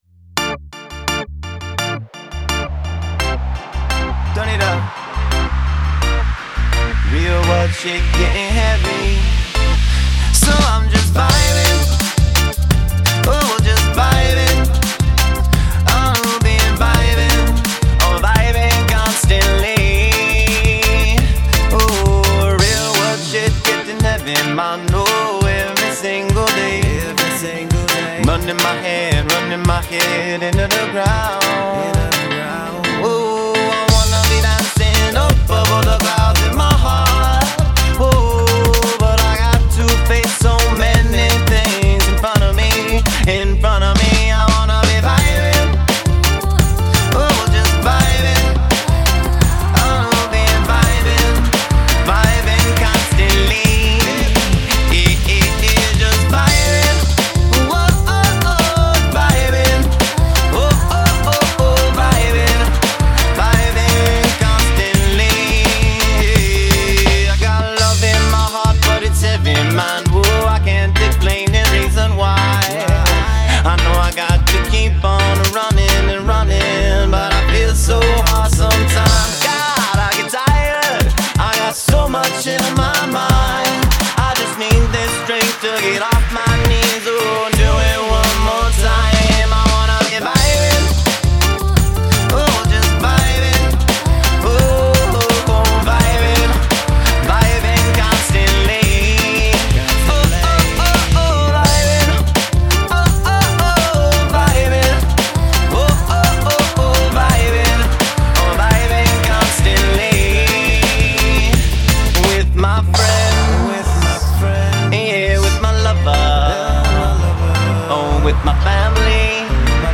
Genre: Telugu